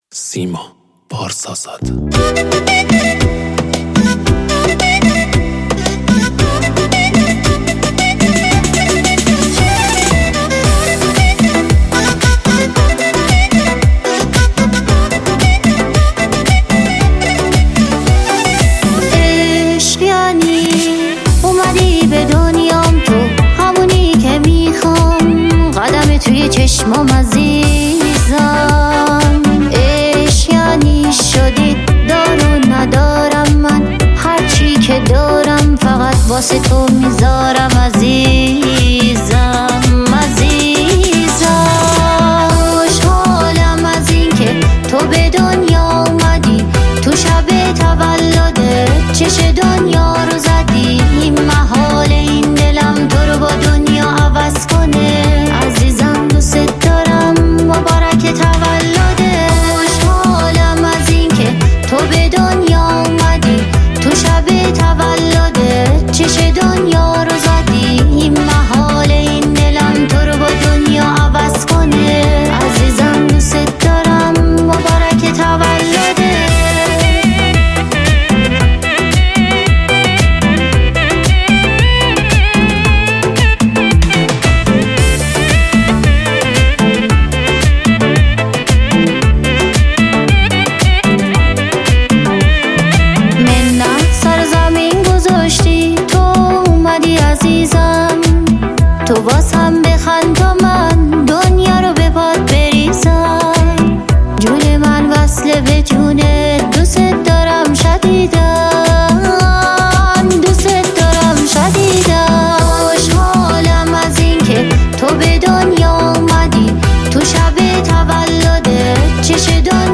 پاپ